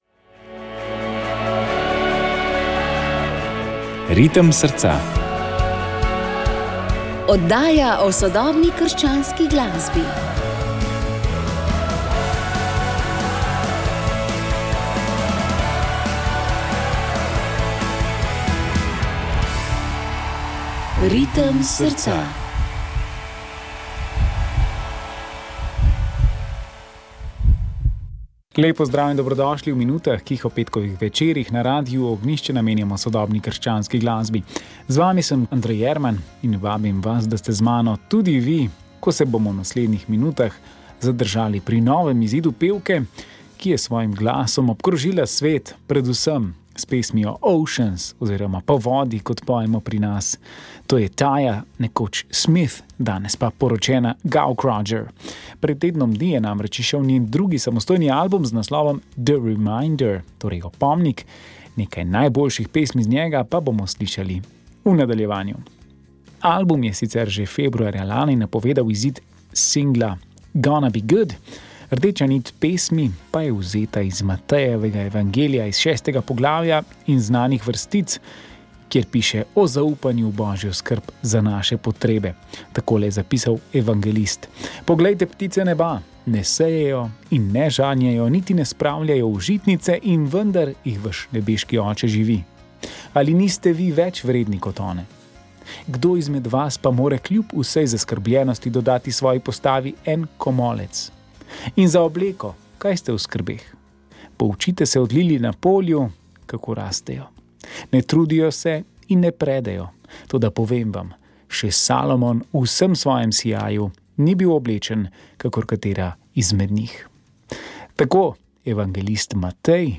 glasba duhovnost sodobna krščanska glasba